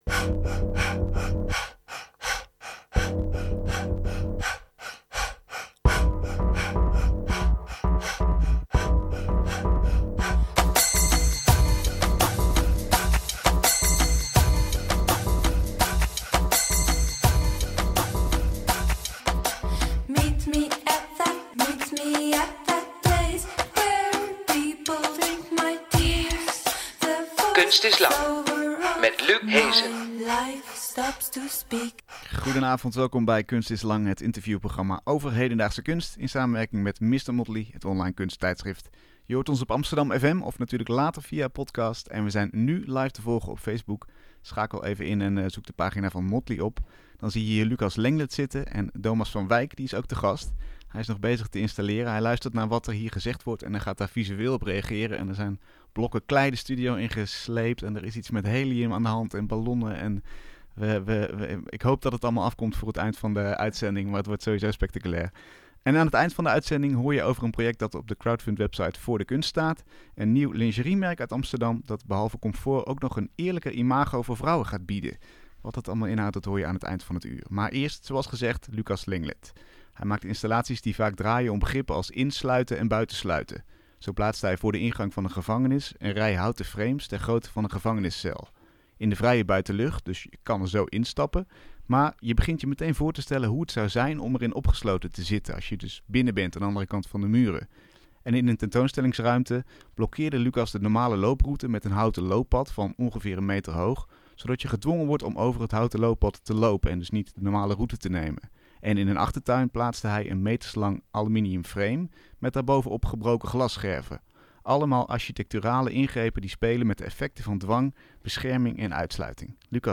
Een gesprek over de betekenis van iets begrenzen, in kunst en in het leven daar buiten.